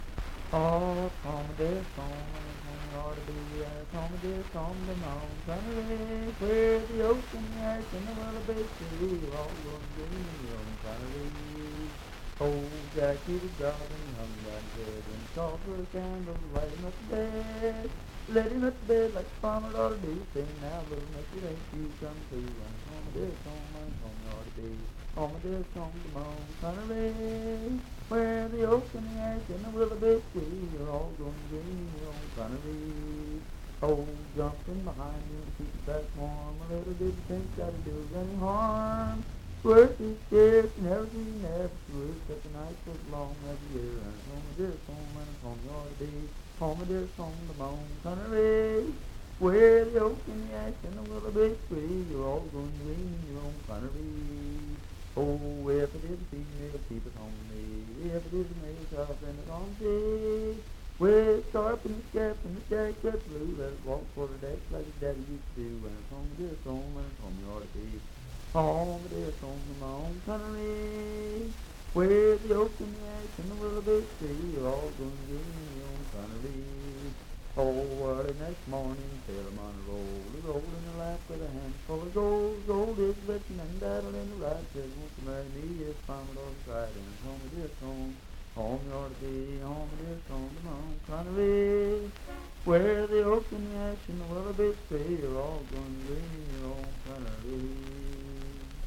Unaccompanied vocal music
Voice (sung)
Pendleton County (W. Va.), Franklin (Pendleton County, W. Va.)